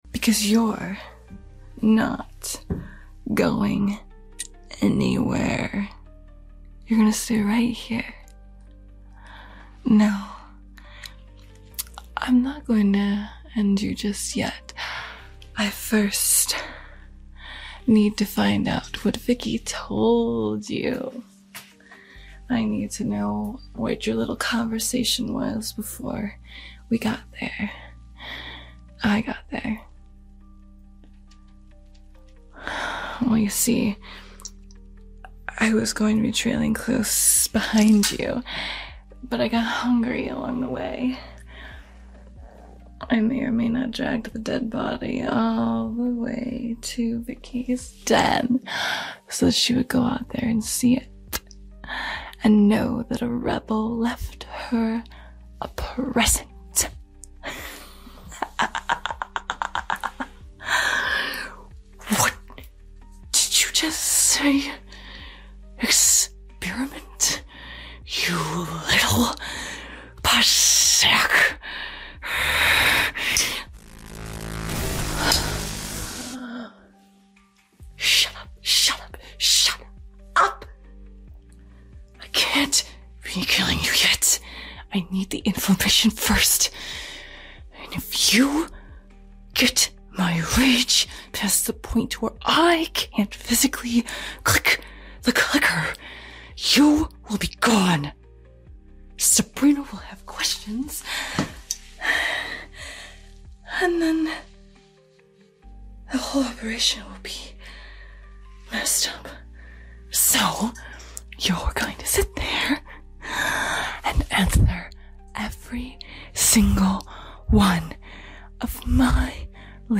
Clip 3 FINALE | ASMR sound effects free download
ASMR Roleplay | Watchdog Chooses A Side | Cyber-Vickiverse Ep 7